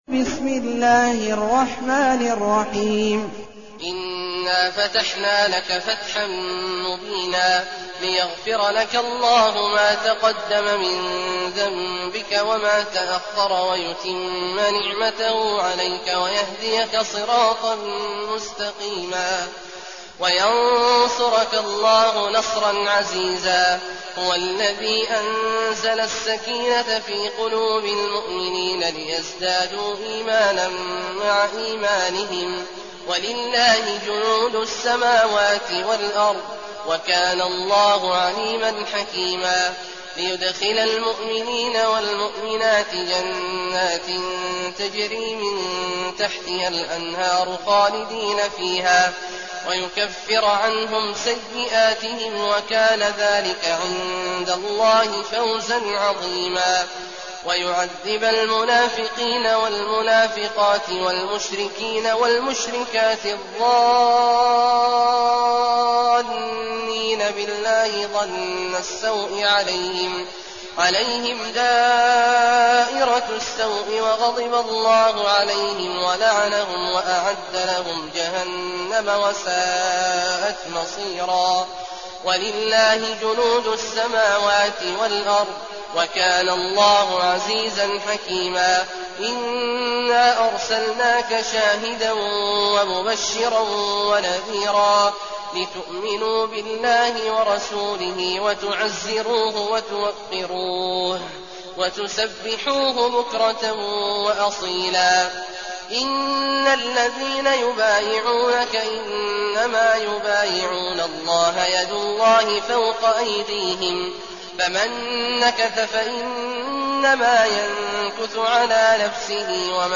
المكان: المسجد النبوي الشيخ: فضيلة الشيخ عبدالله الجهني فضيلة الشيخ عبدالله الجهني الفتح